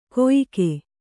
♪ koyike